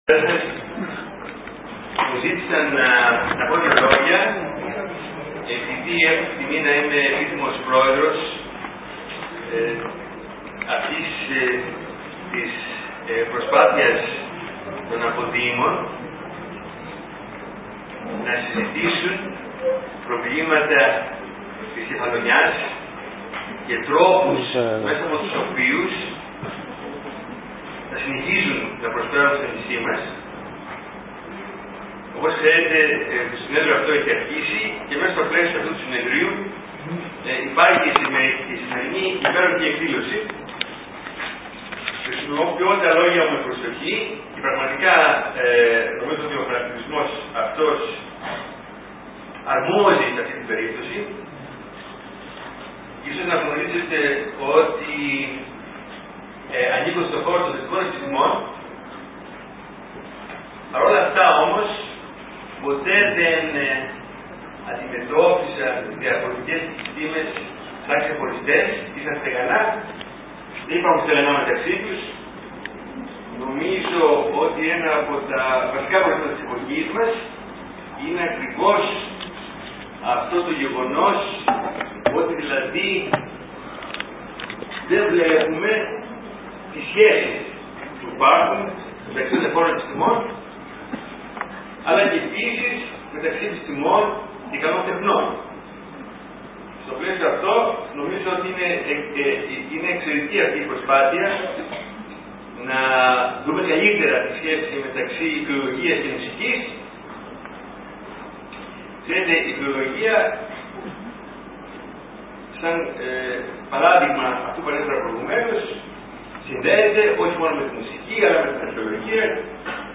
Ακούστε τους χαιρετισμούς και την παρουσίαση